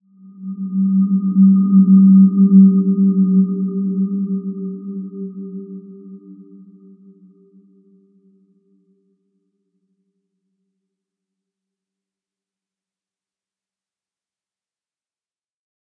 healing-soundscapes
Dreamy-Fifths-G3-mf.wav